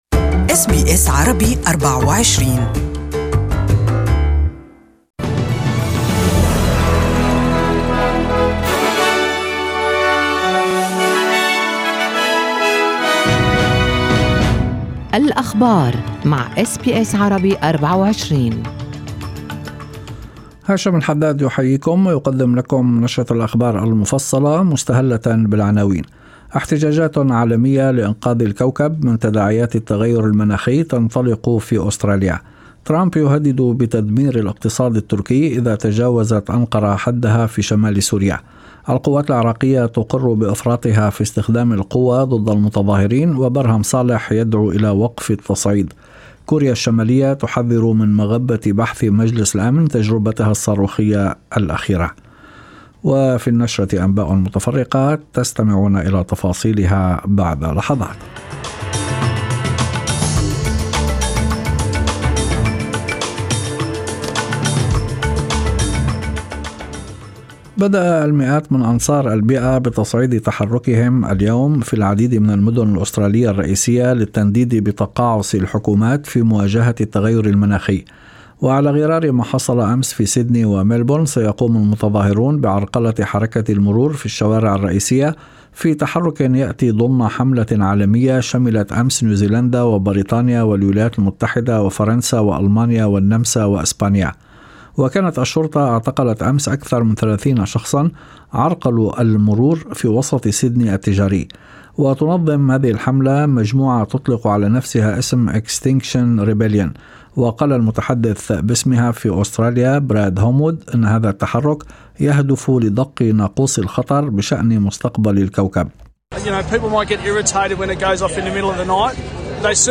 يمكن الاستماع لنشرة الأخبار المفصلة باللغة العربية في التقرير الصوتي أعلاه .